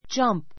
dʒʌ́mp